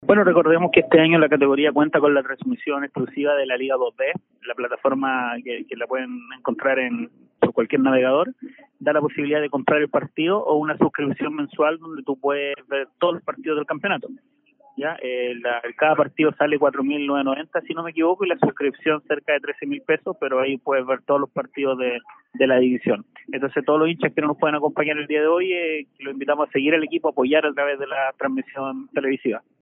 Por lo anterior, esta mañana conversamos con